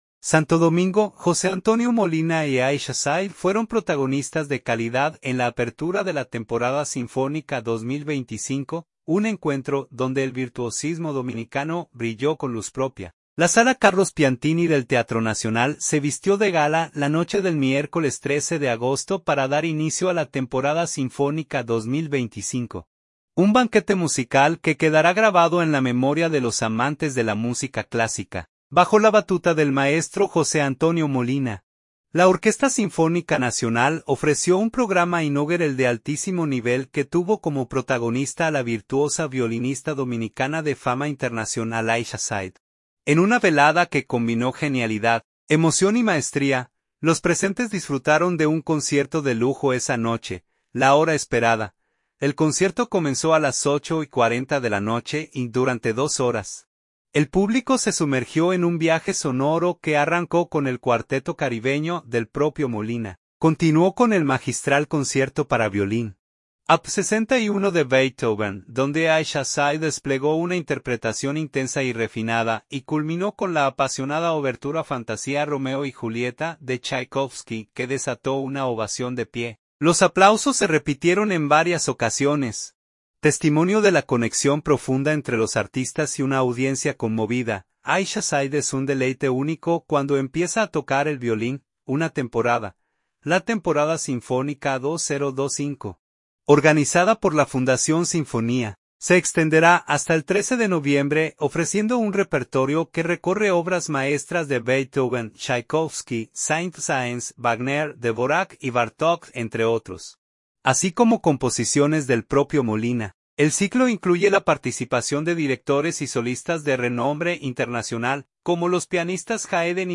Concierto.